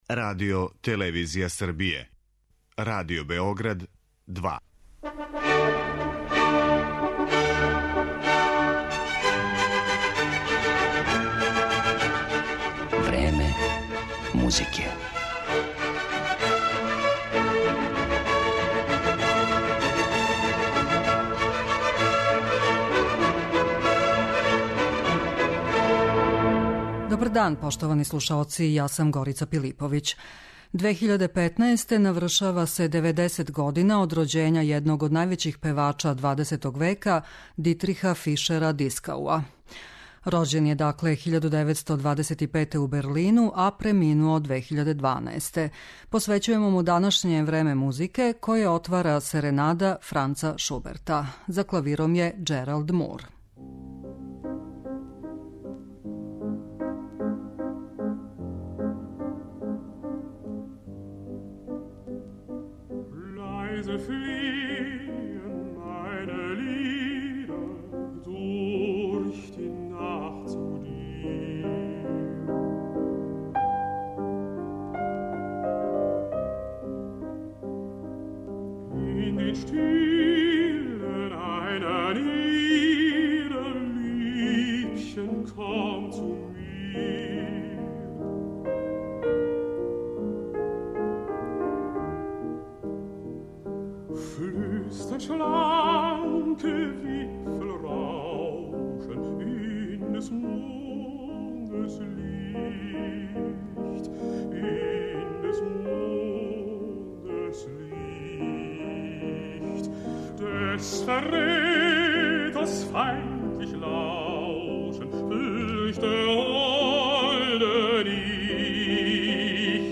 соло-песме